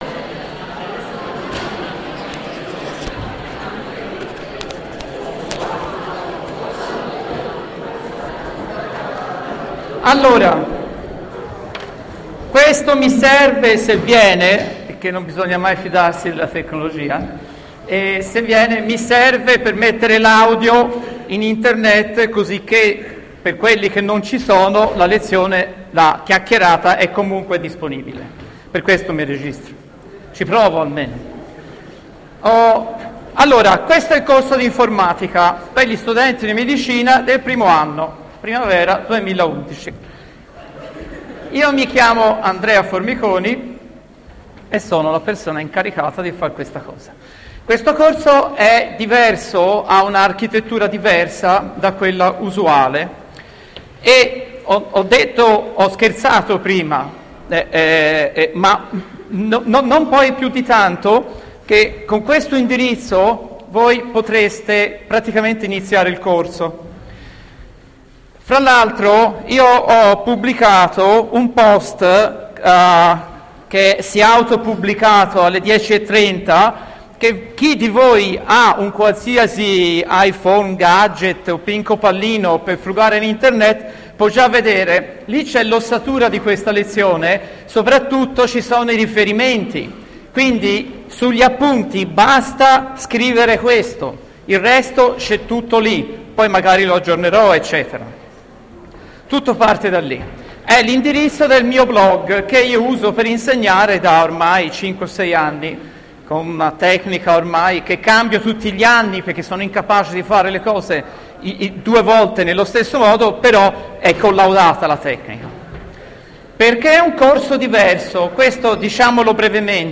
In questo post trovate la registrazione audio della lezione di startup che ha avuto luogo oggi alle 10:30.
Chiedo scusa per la marcata intonazione toscana 🙂